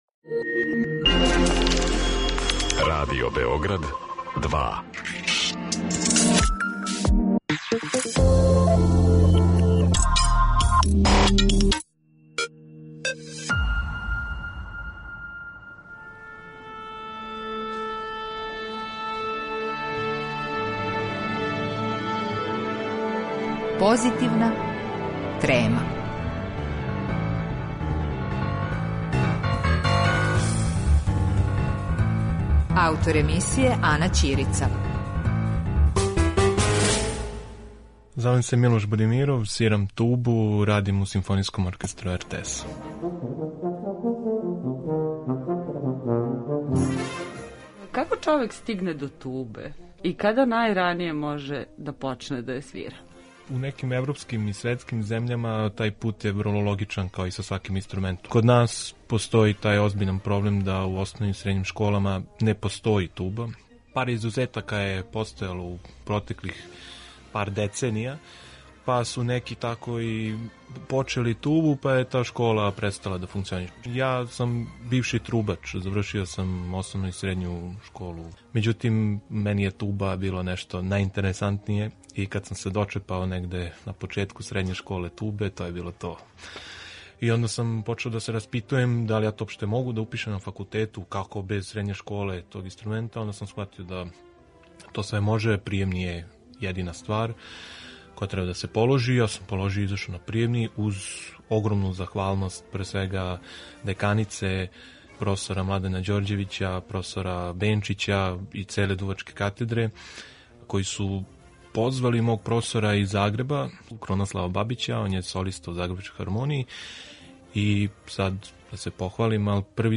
Разговор о туби